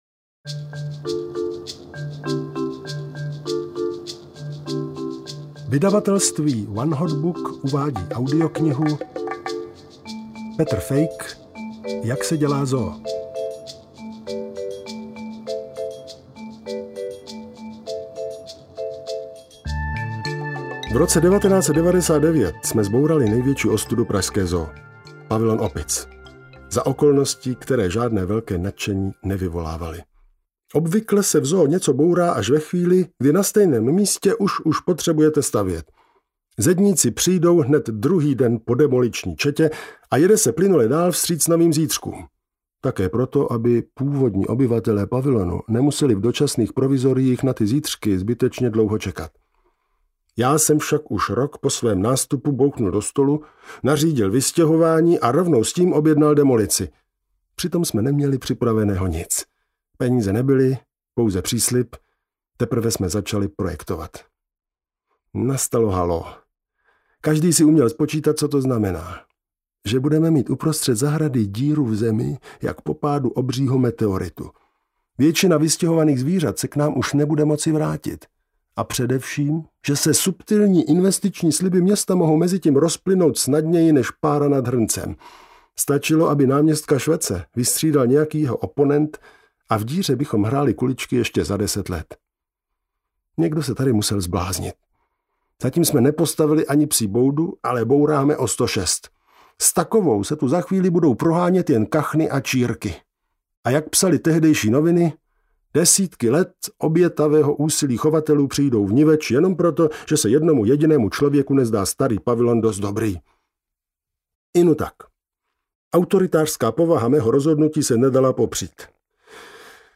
Jak se dělá zoo audiokniha
Ukázka z knihy